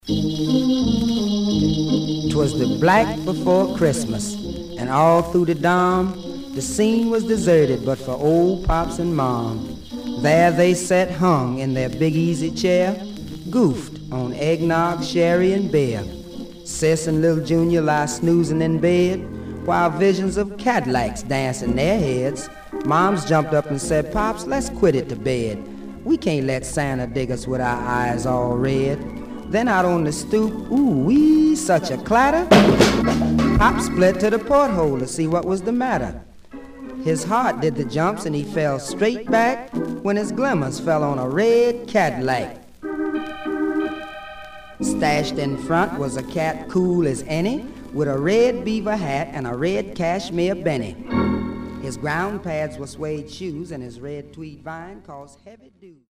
Some surface noise/wear
Mono
Jazz